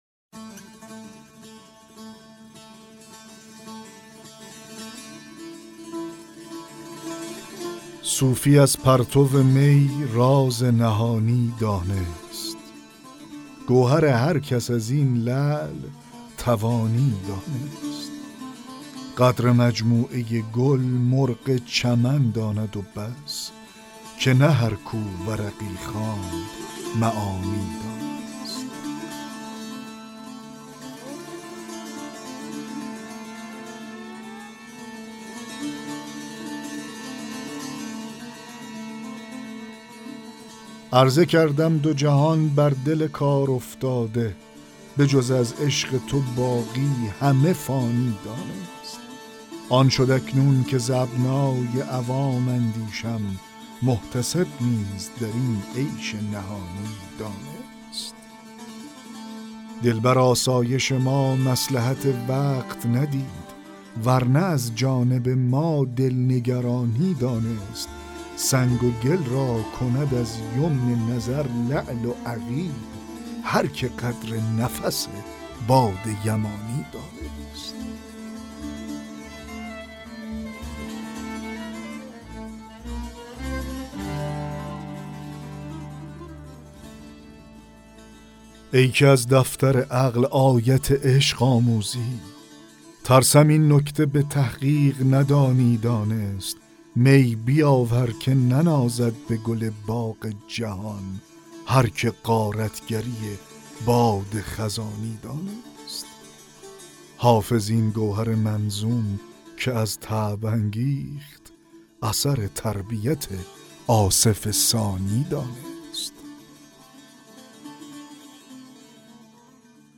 دکلمه غزل 48 حافظ